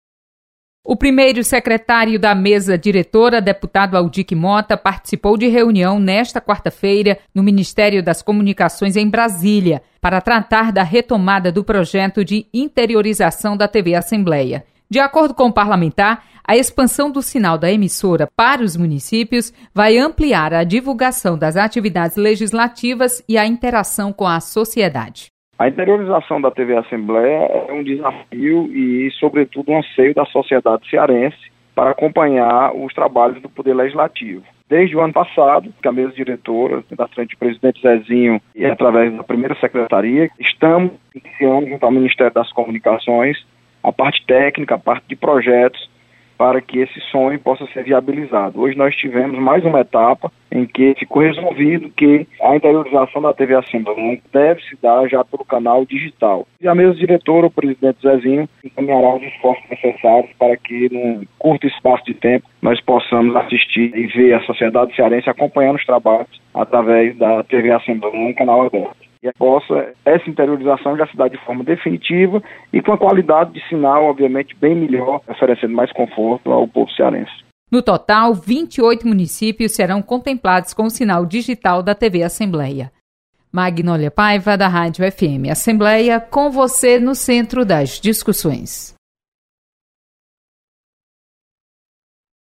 Assembleia quer interiorização da TV do Parlamento. Repórter